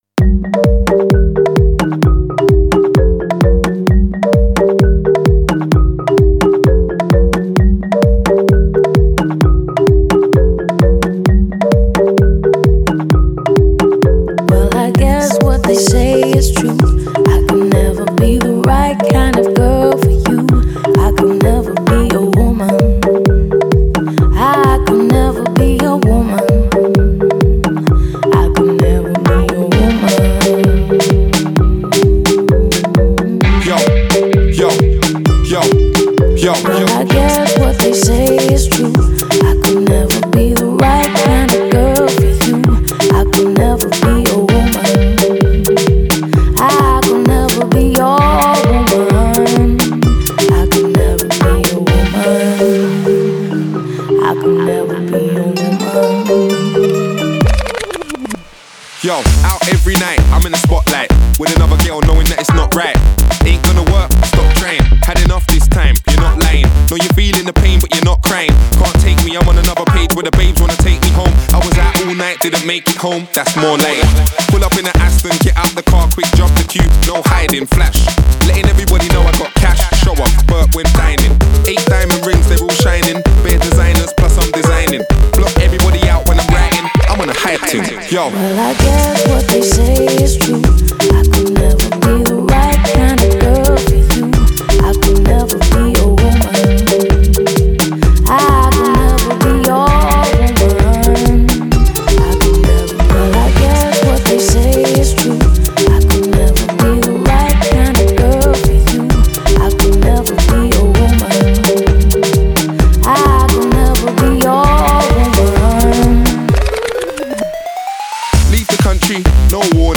UK-Grime